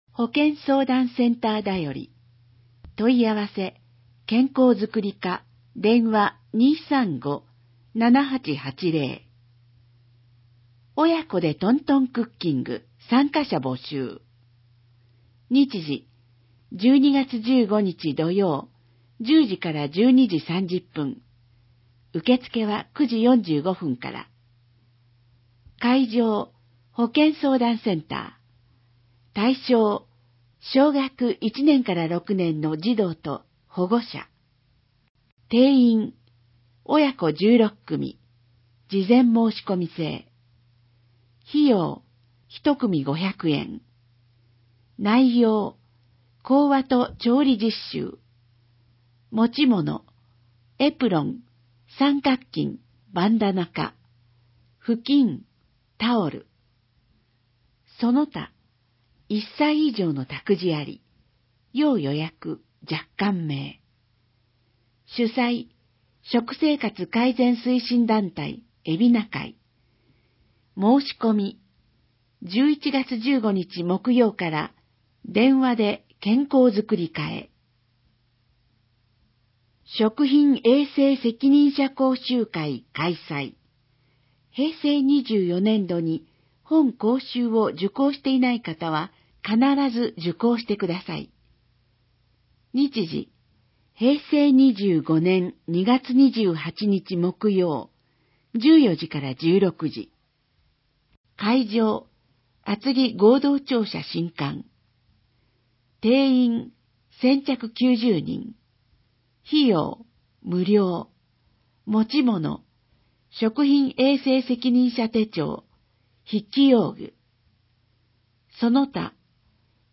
※音声版は、音声訳ボランティア「矢ぐるまの会」の協力により、同会が視覚障がい者の方のために作成したものを登載しています。